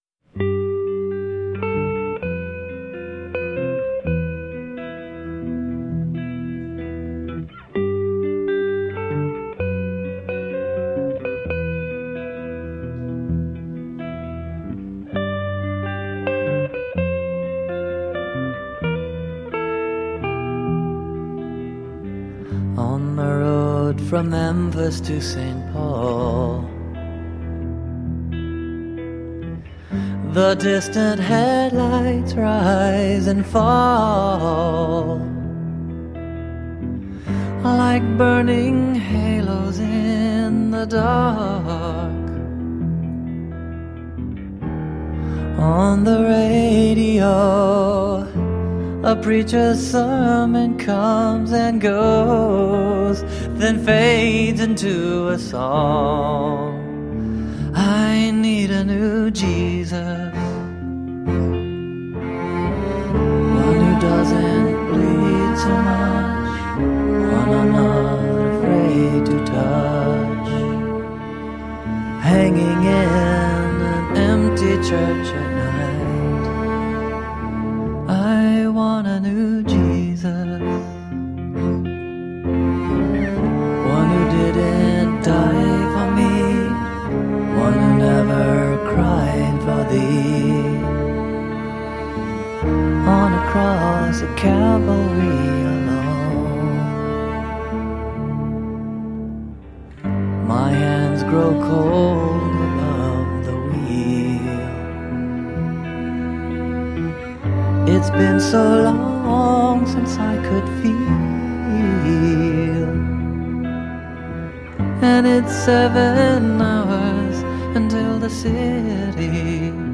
Introspective avant-pop songs.
Tagged as: Alt Rock, Rock, Woman Singing Electro Pop